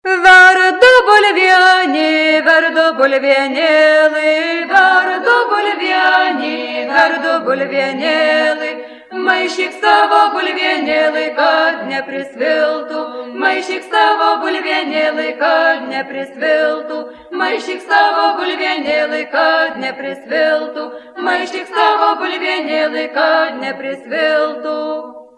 FOLKLORE> DANCES> Circles
South of Lithuania, Varëna region.